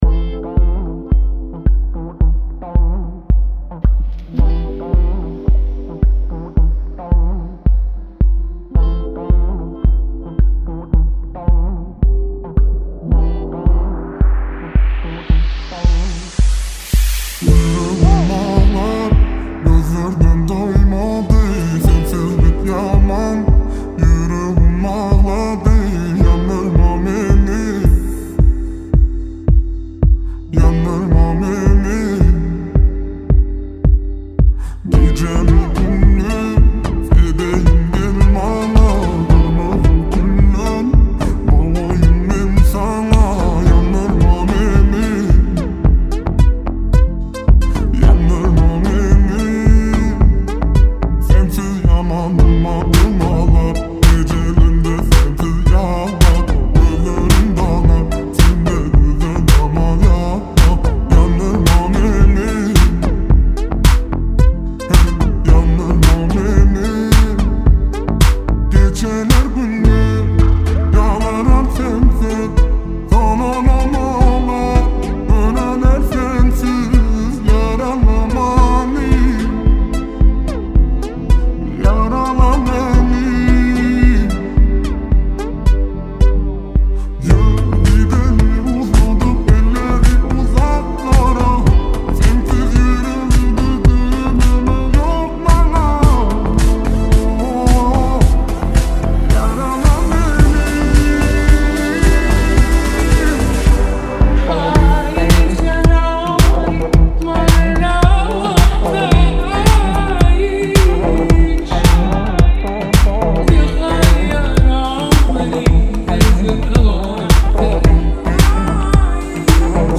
110 Bpm